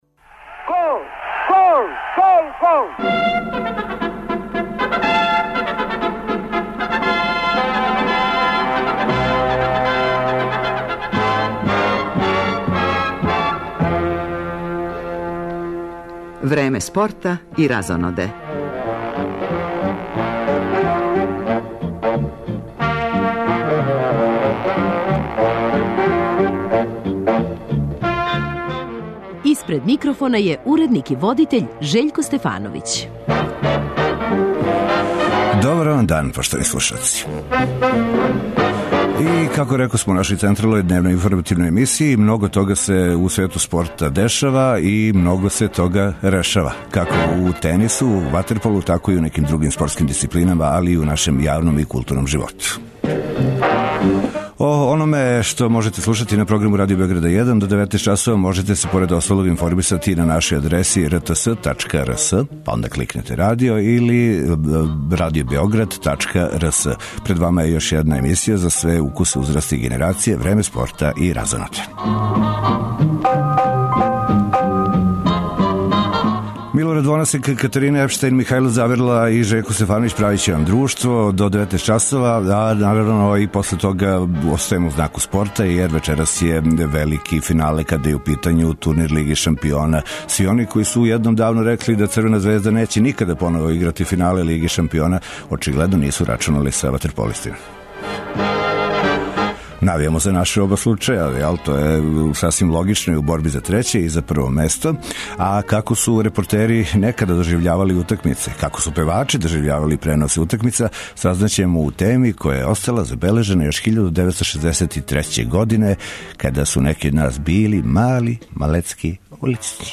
Гост емисије је Момчило Бајагић Бајага, који 22. јуна има концерт у Доњем граду калемегданских зидина, а овом приликом говори о бројним концертима "Инструктора" у земљама региона, све успешнијој каријери у Пољској, новој сарадњи са Здравком Чолићем.